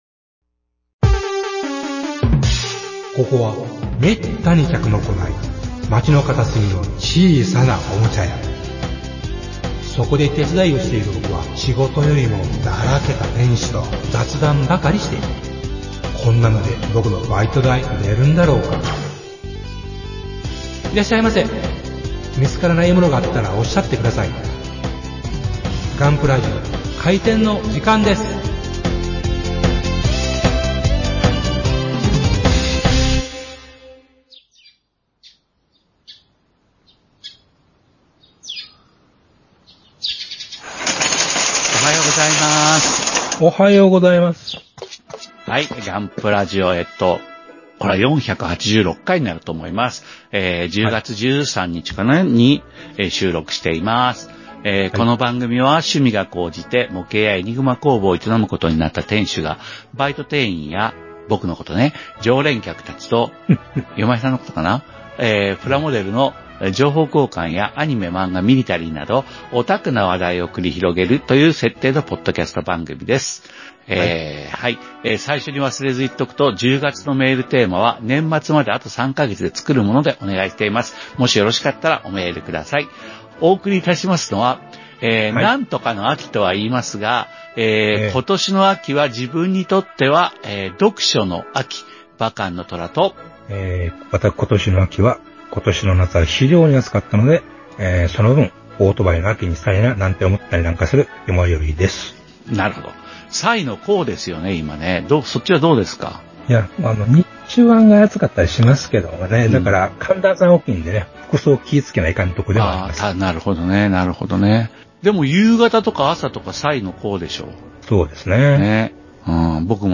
ガンプラジオ ＃４８６（前半） ガンプラジオ 〜プラモデルを中心にオタクな話題を繰り広げる、おばかラジオ〜 podcast